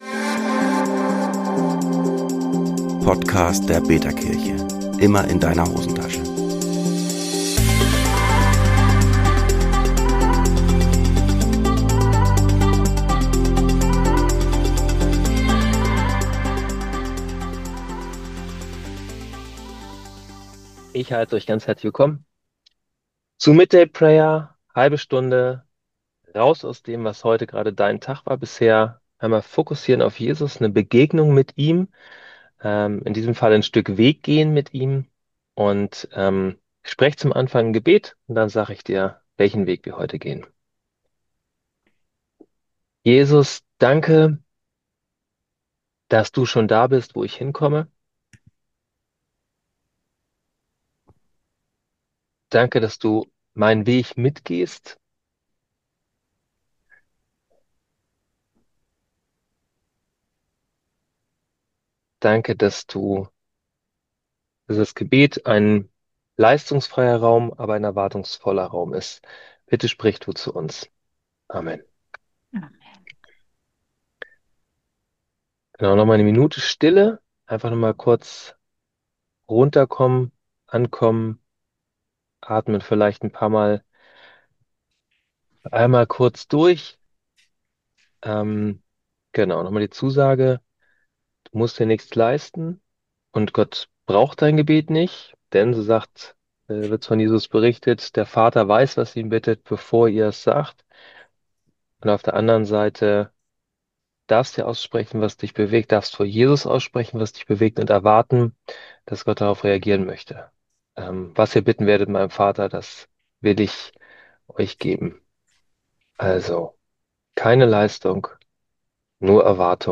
Was passiert, wenn Jesus mitten in unseren Alltagswegen auftaucht, auch wenn wir ihn nicht erkennen? Ein midday prayer der digitalen Gemeindegründung betaKirche – mit Bibeltext, Stille, Fragen und Gebet.